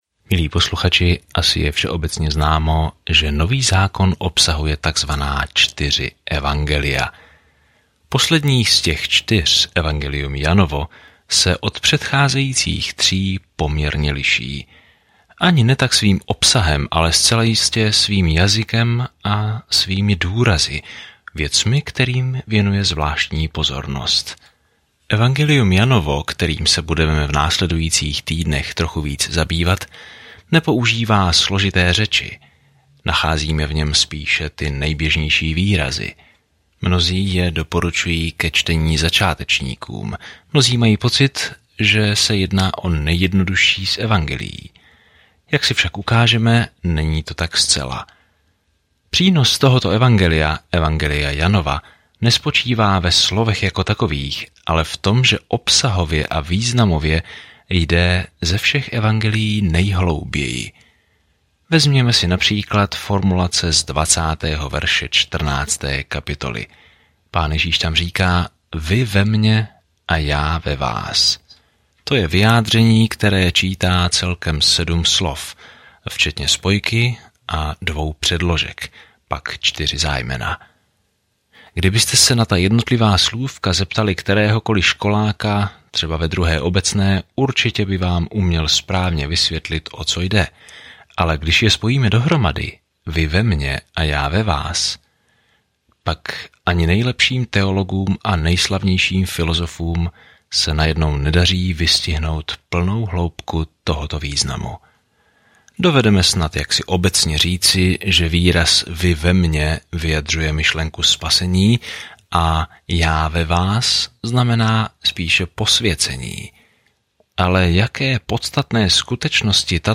Písmo Jan 1:1 Začít tento plán Den 2 O tomto plánu Dobrá zpráva, kterou Jan vysvětluje, je jedinečná od ostatních evangelií a zaměřuje se na to, proč bychom měli věřit v Ježíše Krista a jak žít v tomto jménu. Denně procházejte Janem a poslouchejte audiostudii a čtěte vybrané verše z Božího slova.